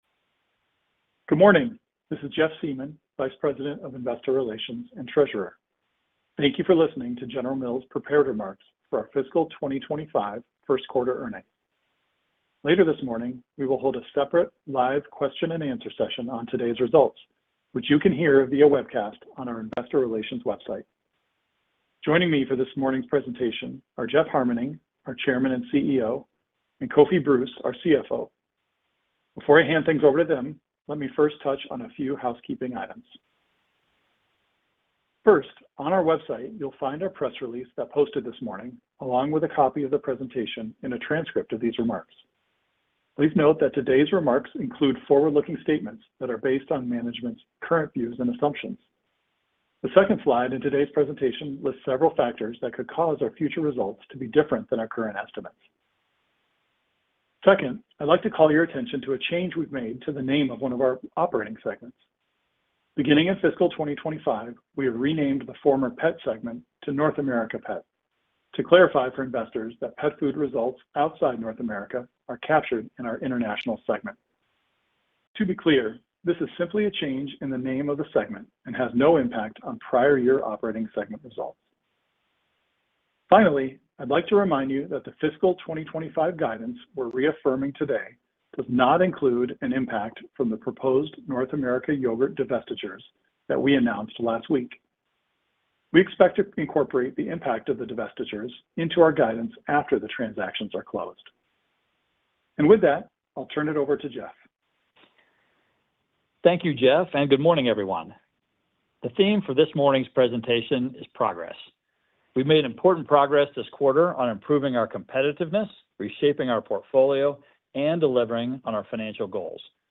Recording - Prepared Remarks (opens in new window)